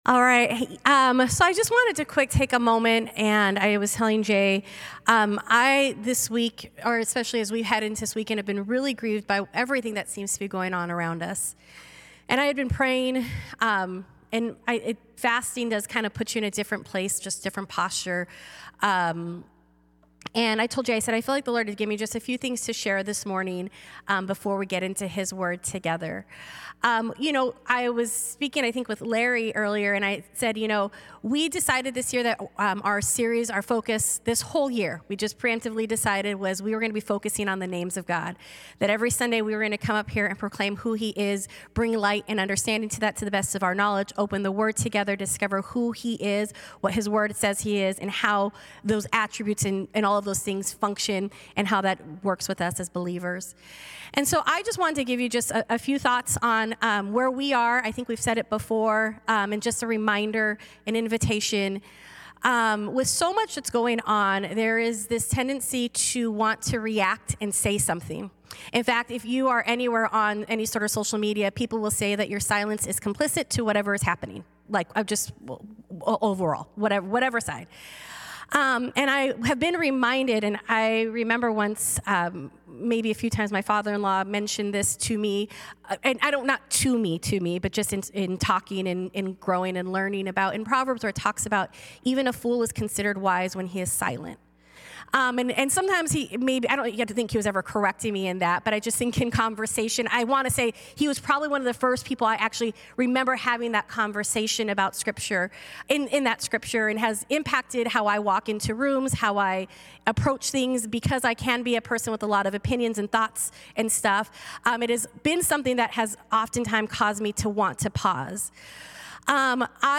A message from the series "Names Of God."